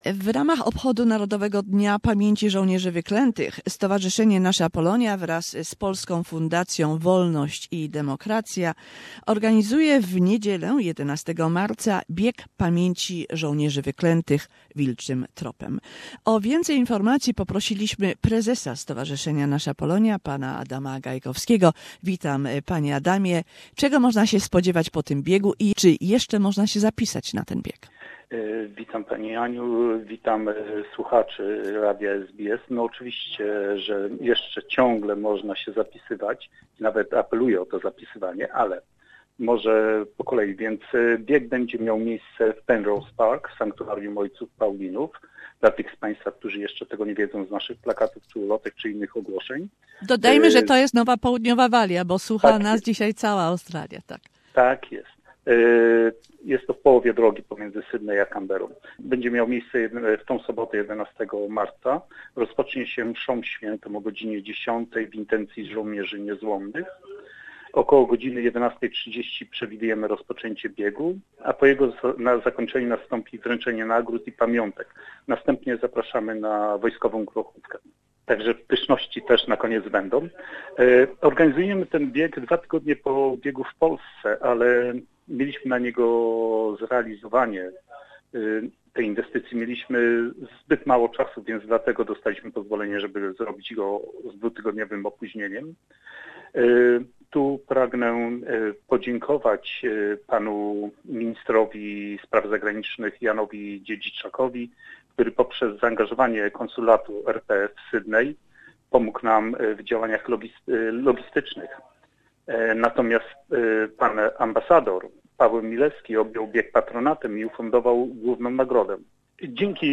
Two interviews with two prominent members of Polish Community in Australia